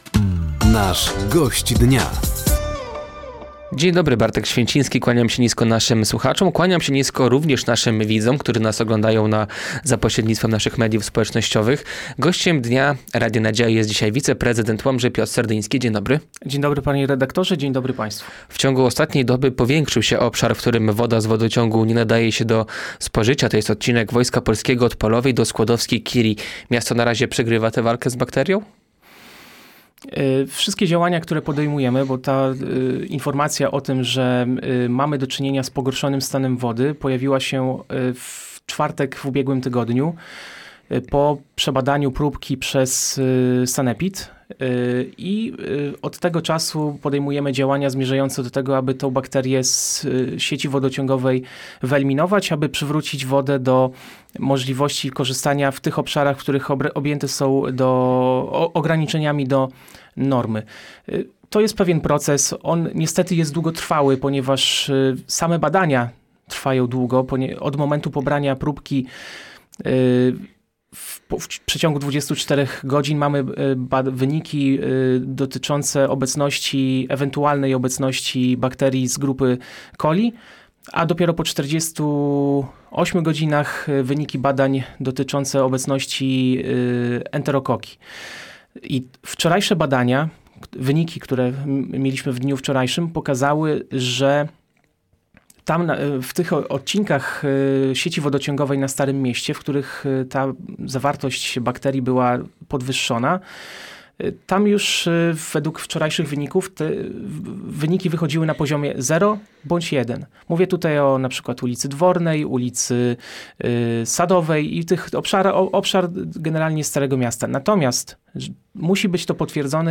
Gościem Dnia Radia Nadzieja był wiceprezydent Łomży Piotr Serdyński. Tematem rozmowy była sytuacja w mieście związana z bakterią coli w wodociągu oraz mocny komentarz na naszym Facebooku w kierunku drugiego zastępcy prezydenta Andrzeja Stypułkowskiego.